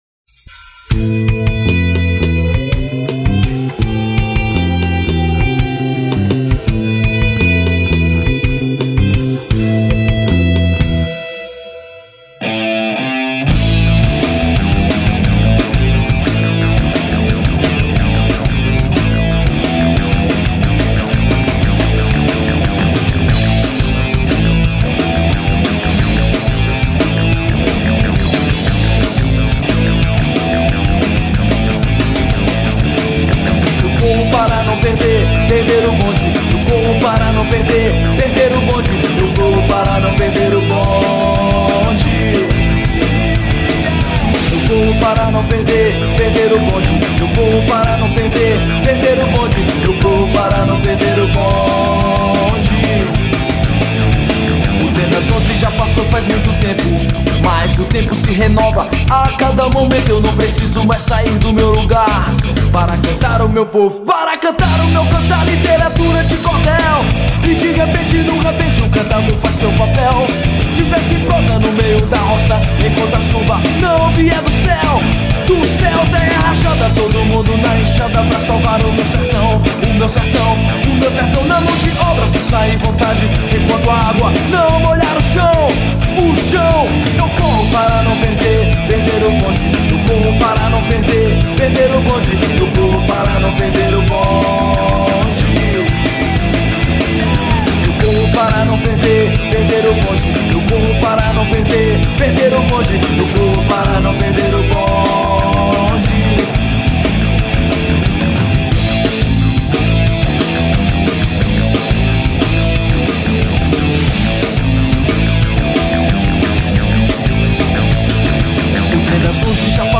2774   03:52:00   Faixa: 8    Rock Nacional